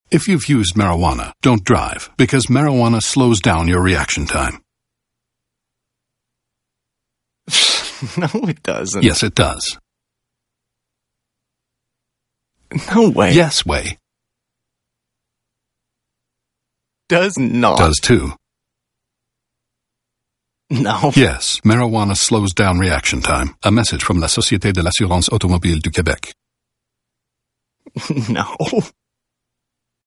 SilverPublic Service - Radio Single